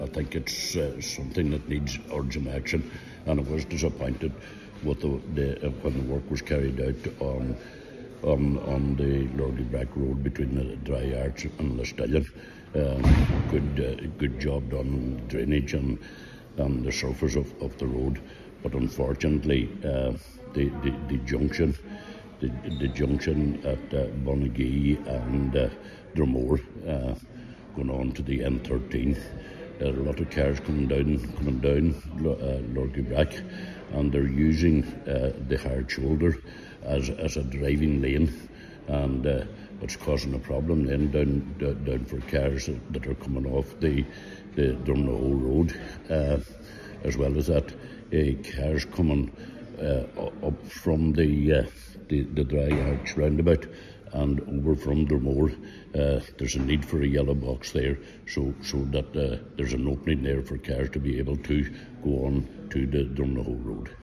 Councillor Coyle says the use of the hard shoulder is causing difficulty for motorists exiting from other roads: